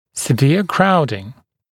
[sɪ’vɪə ‘kraudɪŋ][си’виа ‘краудин]выраженная скученность, высокая степень скученности